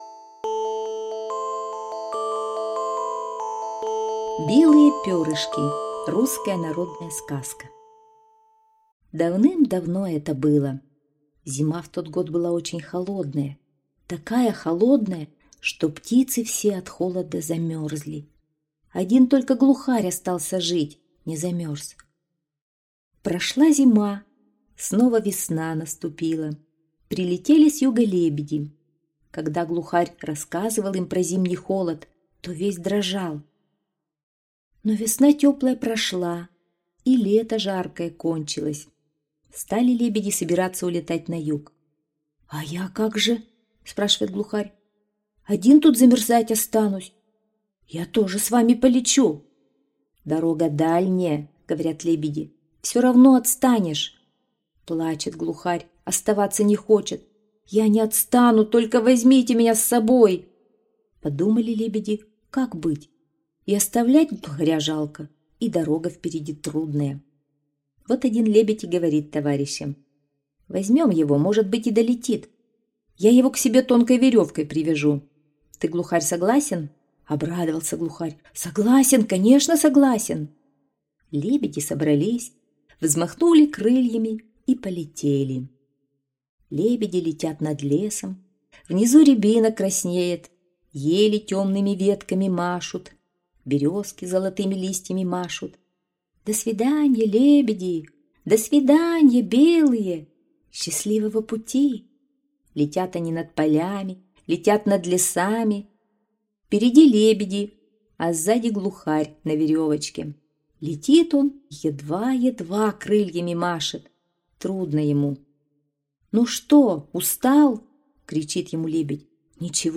Русские народные аудиосказки